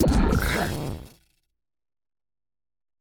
Grito de Bellibolt.ogg
Grito_de_Bellibolt.ogg.mp3